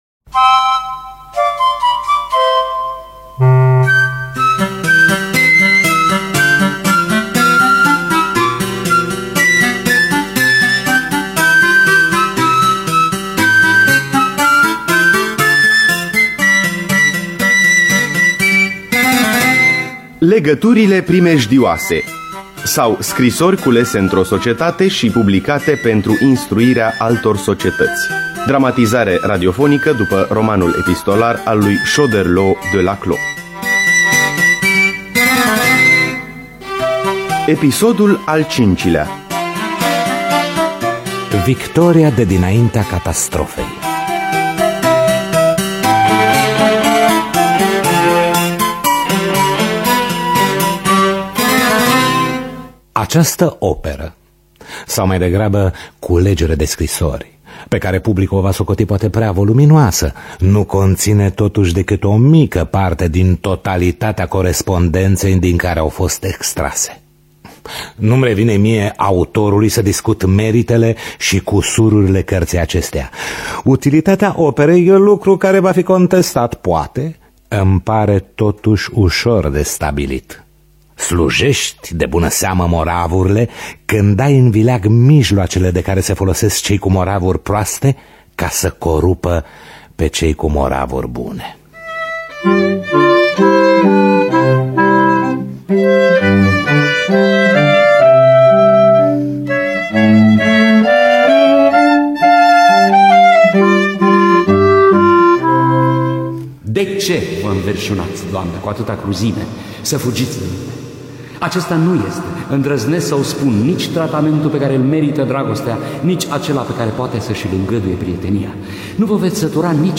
Dramatizarea radiofonică
violoncel
flaut
vioară